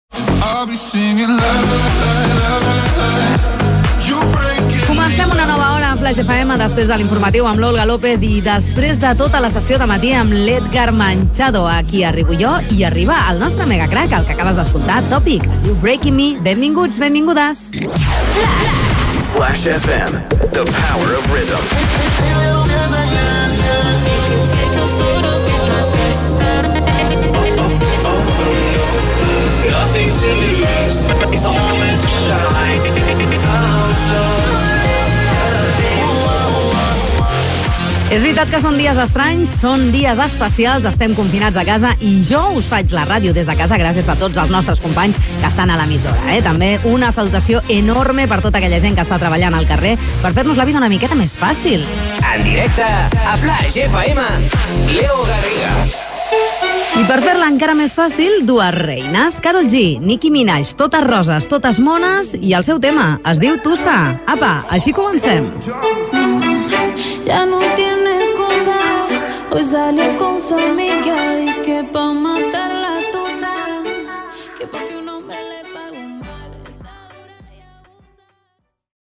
Inici d'una nova hora, benvinguda, indicatiu de l'emissora, comentari que el programa es fa des de casa degut al confinament de la pandèmia de la Covid-19, tema musical
Musical